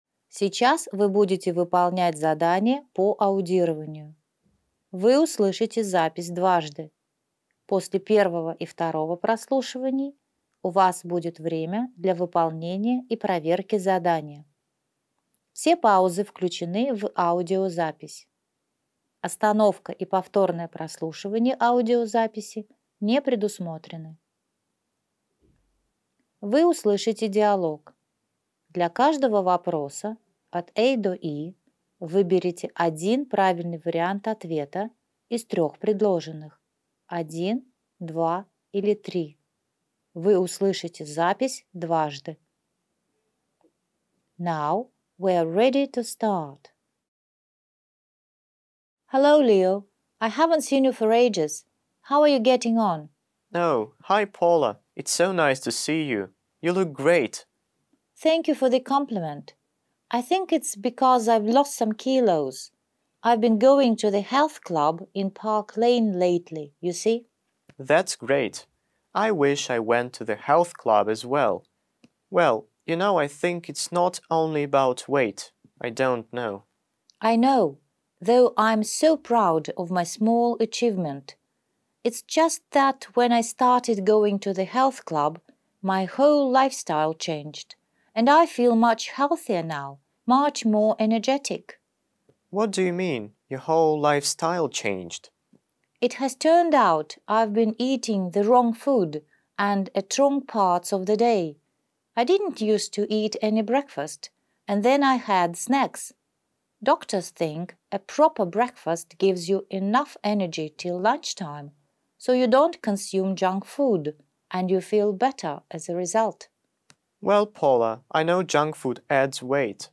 1. Вы услышите диалог.